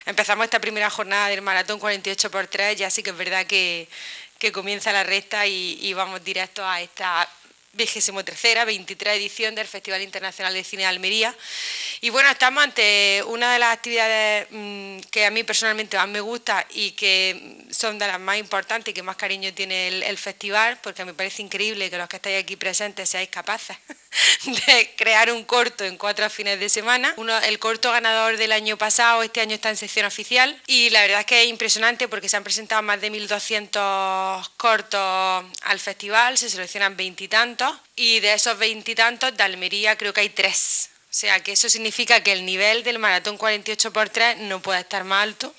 19-10_maraton_diputada.mp3